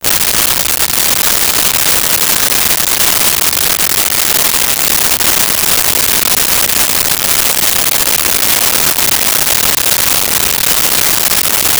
Fire Intense Crackle
Fire Intense Crackle.wav